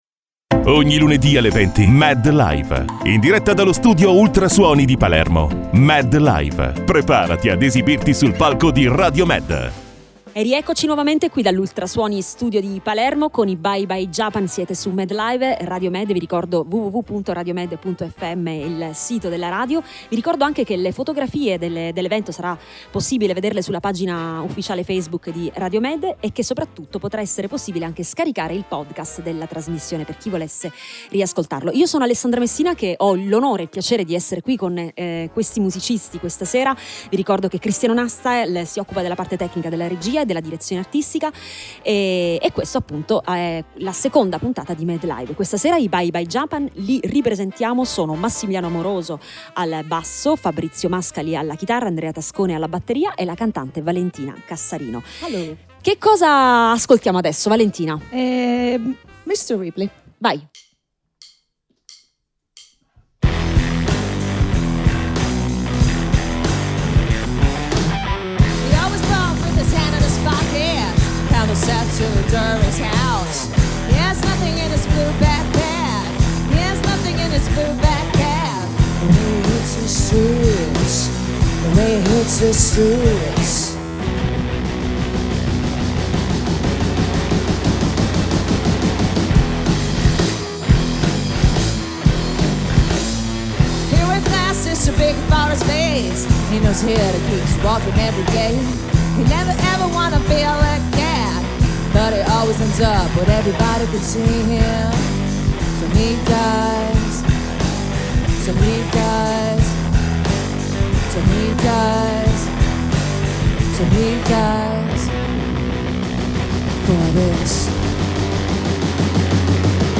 interamente dedicato alla musica dal vivo
chitarre
batteria